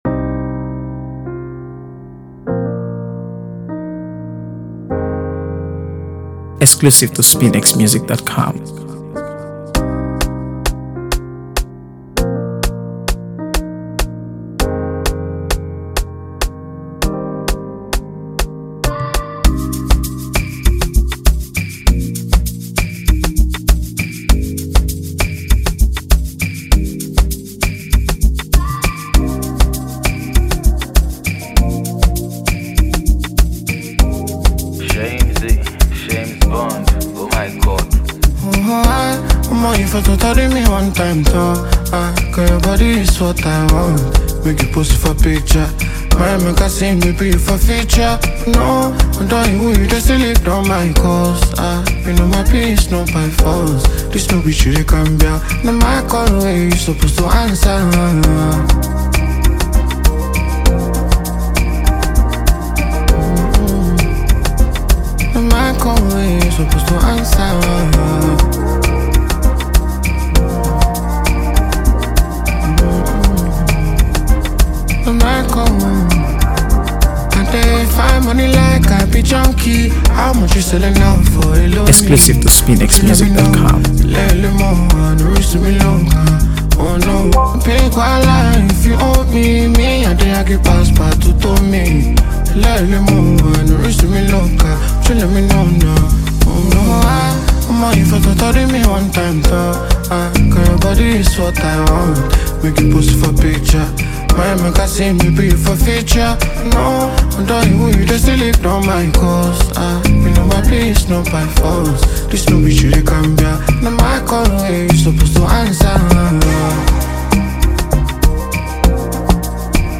AfroBeats | AfroBeats songs
upbeat energy and memorable chorus